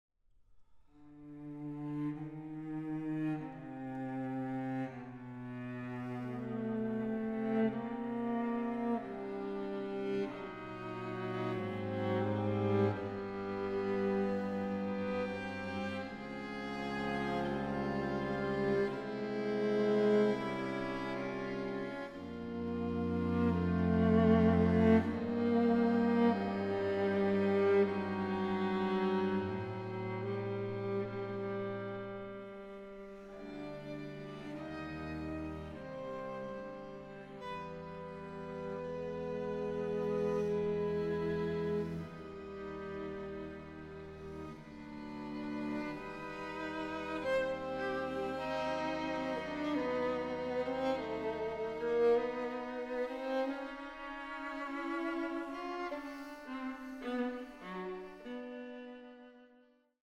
String Quartet No. 8 in C minor, Op. 110